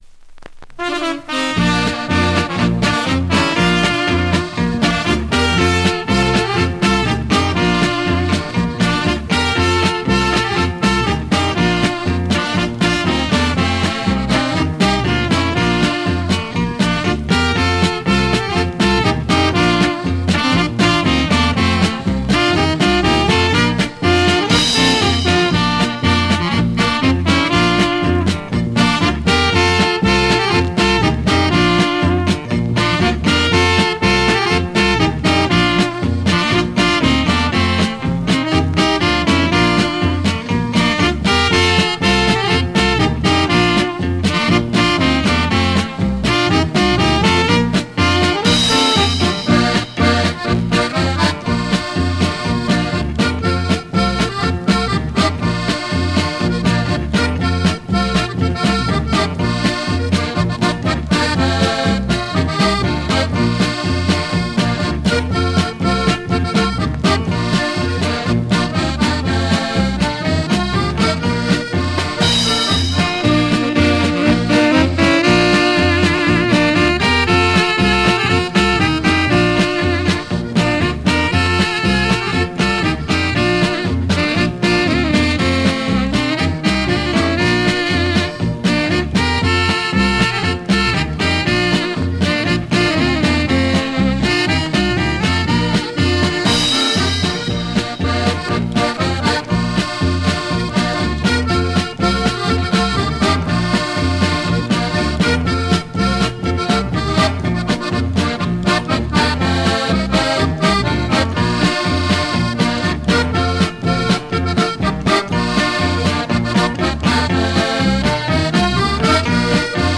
Recorded live 1985
Commentary 8.